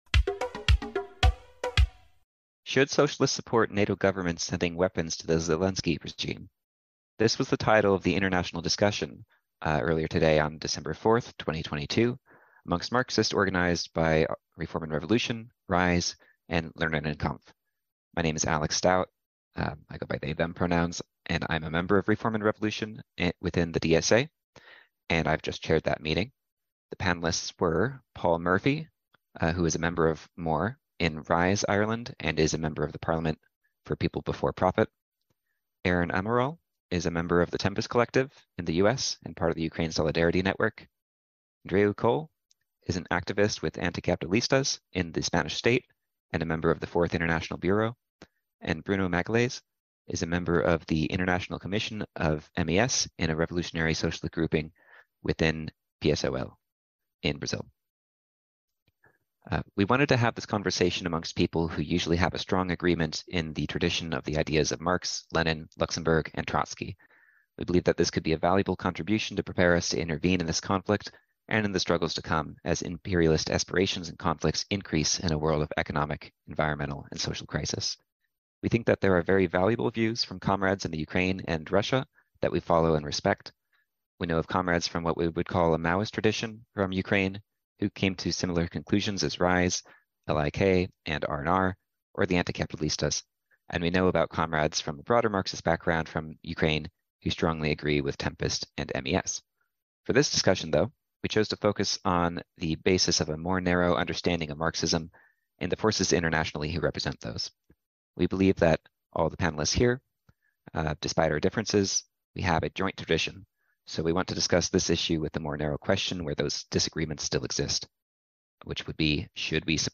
International Debate on the War in Ukraine – Should Socialists Support NATO governments sending weapons to the Zelensky regime?